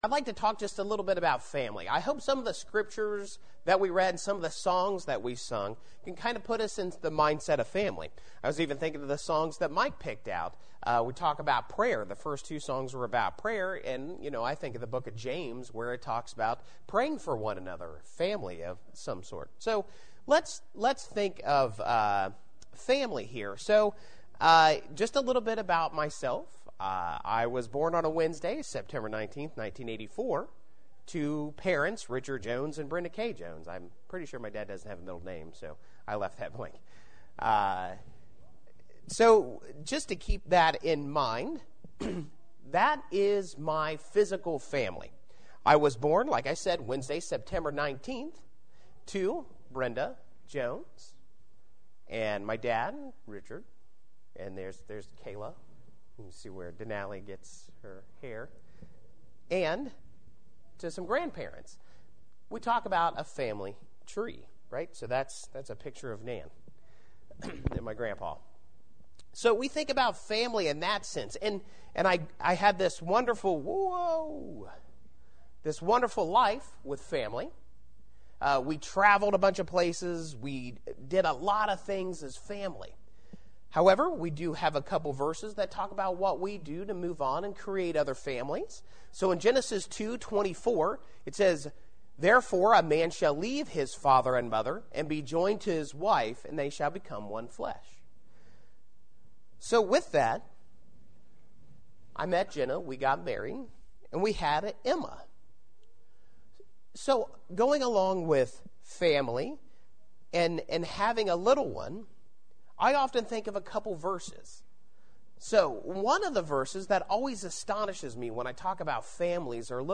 Family – Lesson One